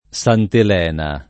Santel$na] (antiq. santalena [Santal$na]) s. f. (numism.) — moneta bizantina con un’effigie già ritenuta di sant’Elena (donde il nome, con l’acc. del gr. ῾Ελένη / Heléne [hel$ne]) — sim. il cogn. Santalena